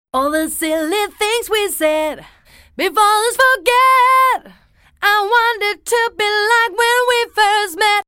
107vox-org1.mp3